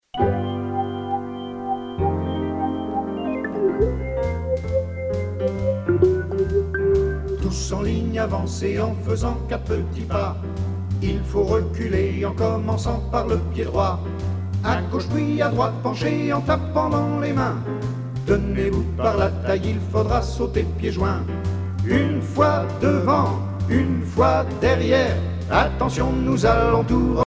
danse : madison
Pièce musicale éditée